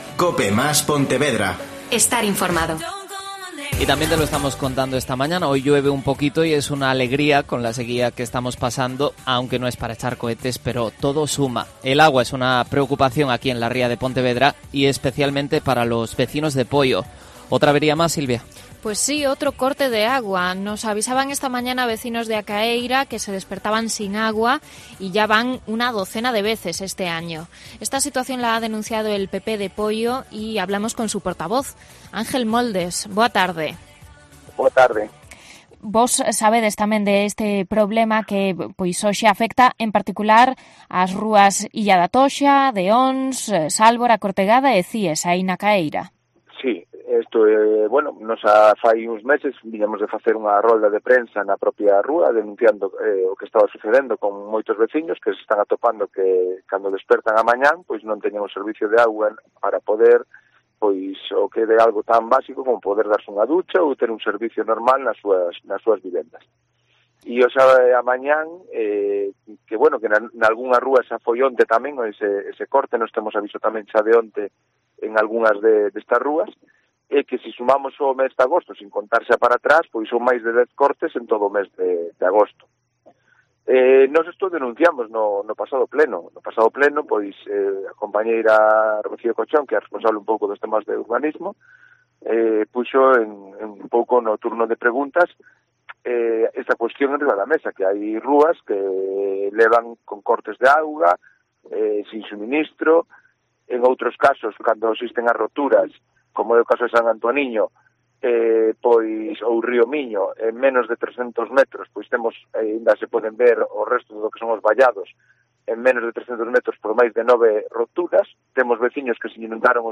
AUDIO: El portavoz del PP de Poio, Ángel Moldes, y el concejal de Obras y Servicios de Poio, Julio Casás, valoran la incidencia que...